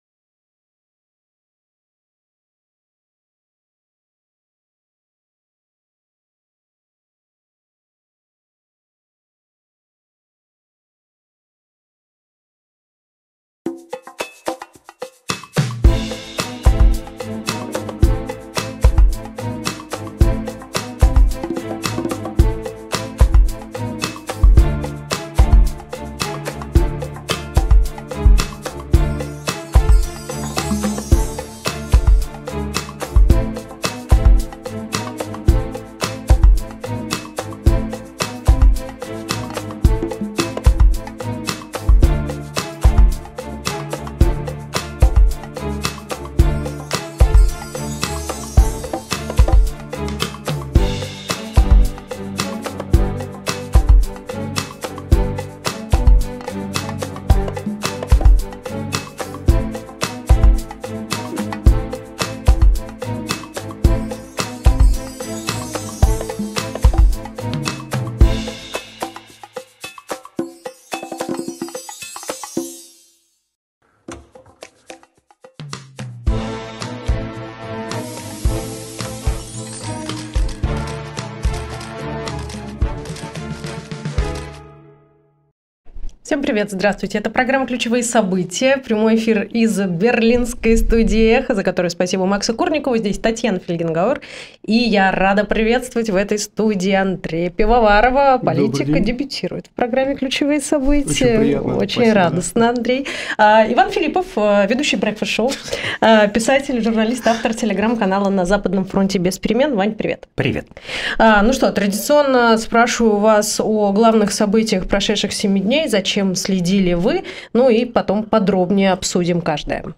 Эфир ведёт Татьяна Фельгенгауэр
klyuchevye-sobytiya-v-pryamom-efire.-andrej-pivovarov-i-ivan-filippov.mp3